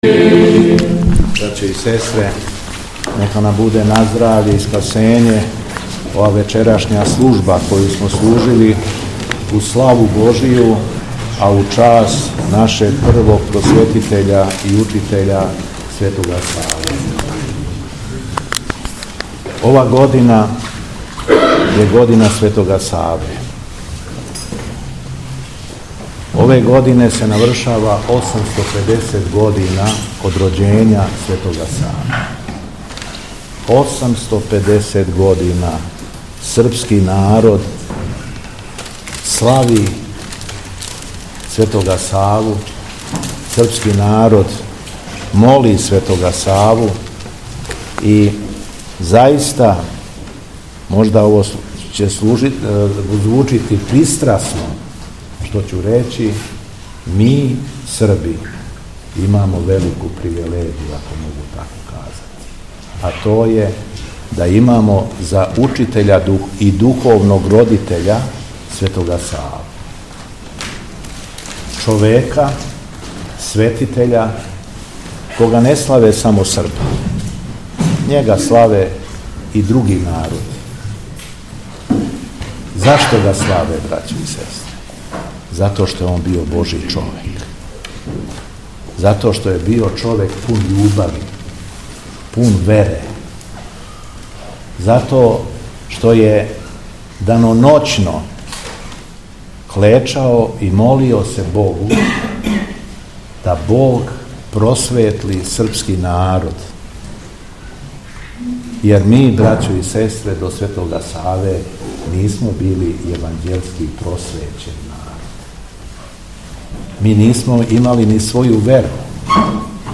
Беседа Његовог Високопреосвештенства Митрополита шумадијског г. Јована
Високопресовећени Митрополит Јован беседио је срчано и надахнуто: